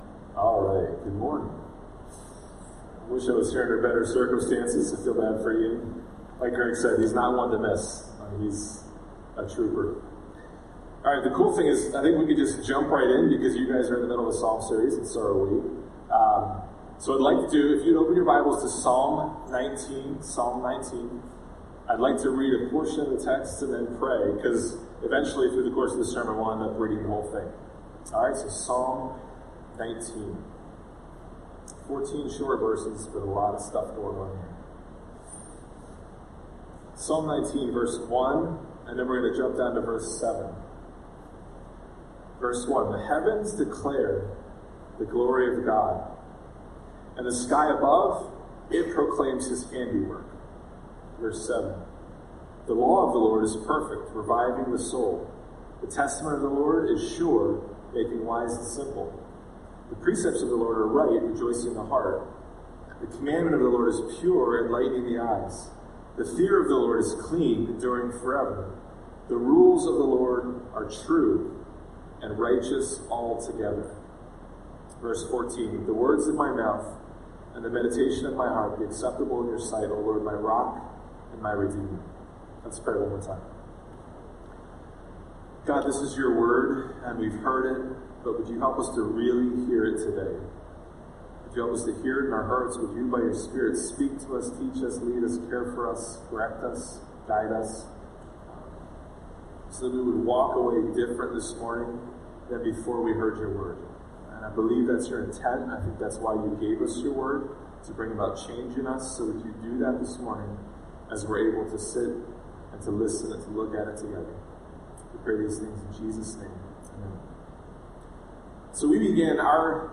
A sermon from the series "The Psalms." Psalm 130 is written out of the depths, where we can find ourselves believing that we're alone.